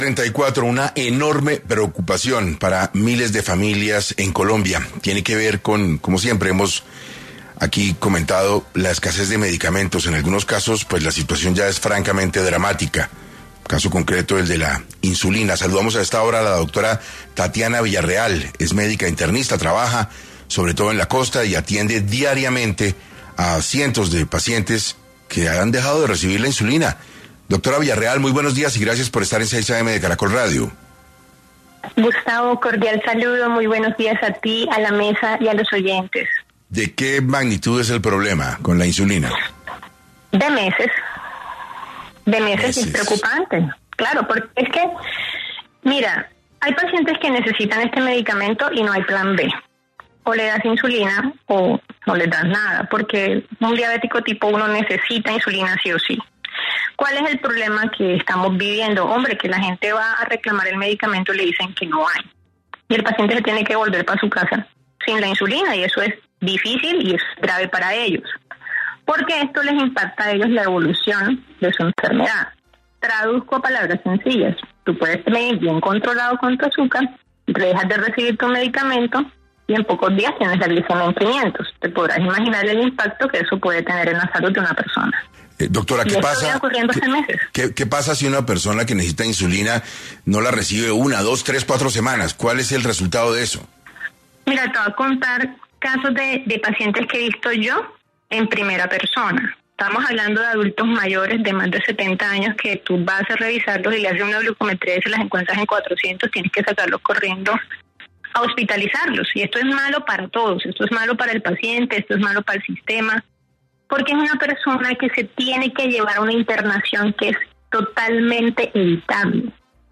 En 6AM una experta en el área de la salud aseguró que la irregularidad del suministro de insulina en el país va ligado a la falta de pagos en el sistema de salud